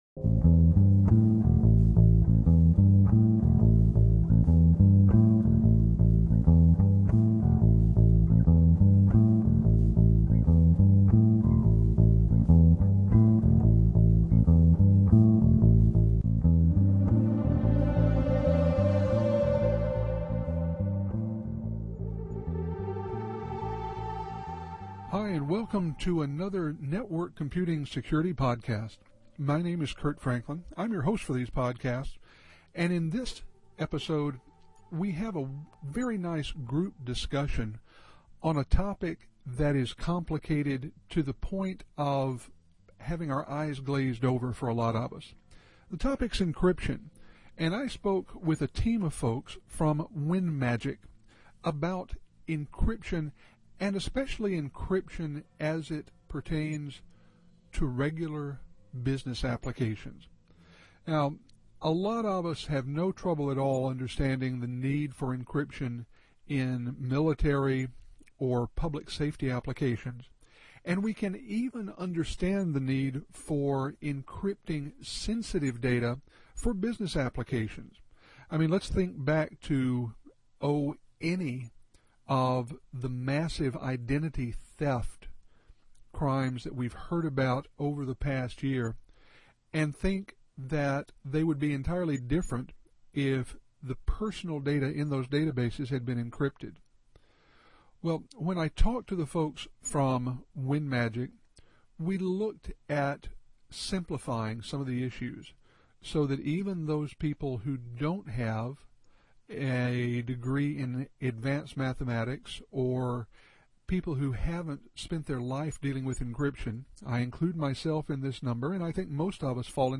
I recently had a phone call with a team of folks from WinMagic, and we discussed encryption as part of a full security program.